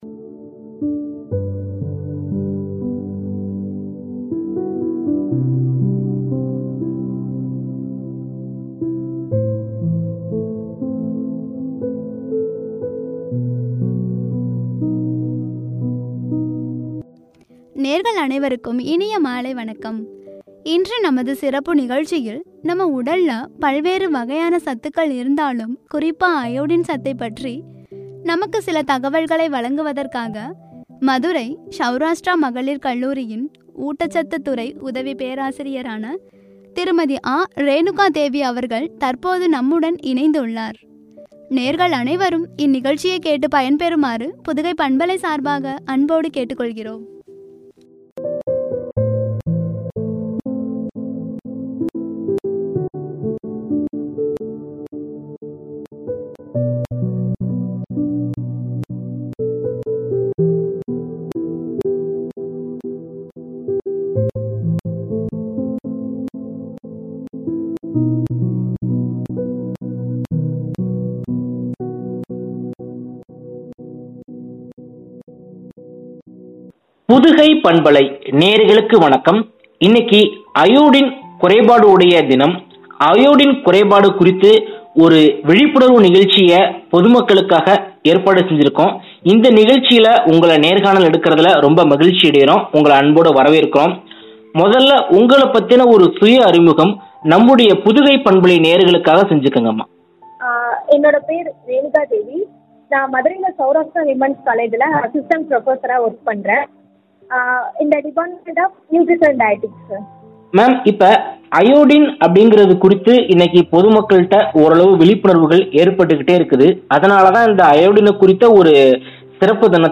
“அயோடின் அவசியம்”என்ற தலைப்பில் வழங்கிய உரையாடல்